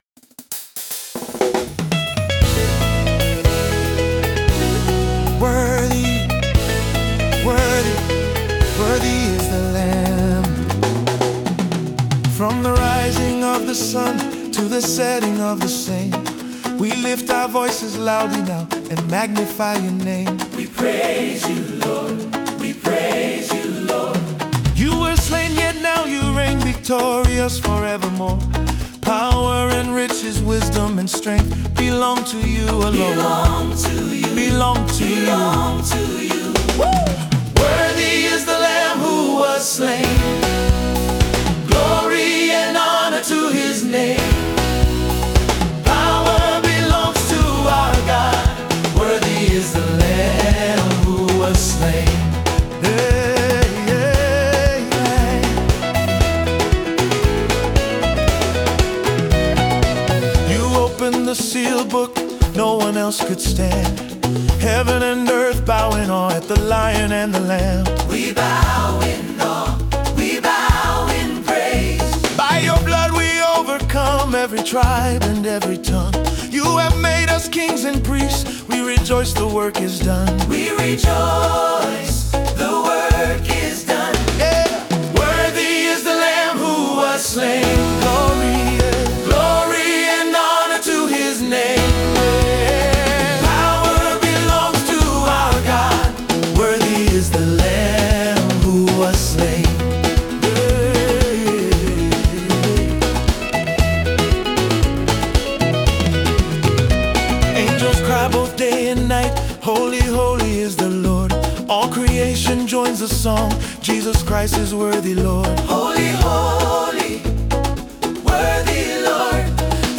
Through heartfelt lyrics and uplifting melodies